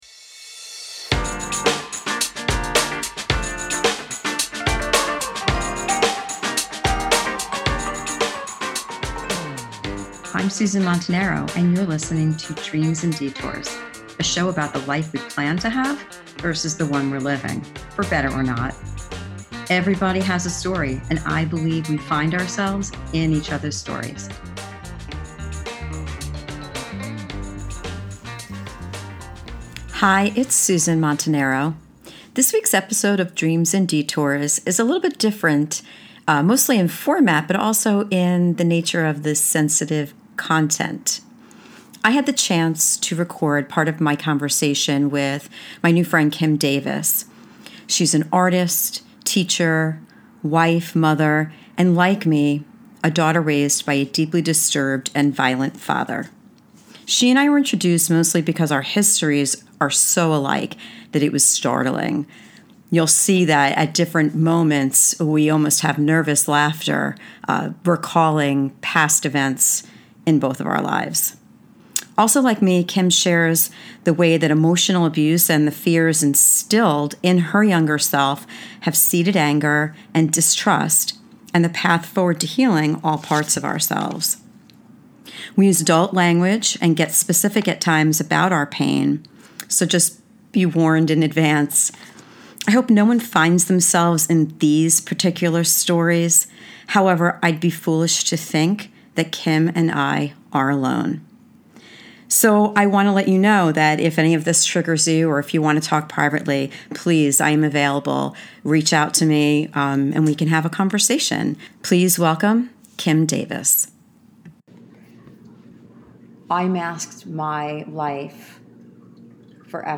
This week’s episode is a discussion about being raised with violence and fear.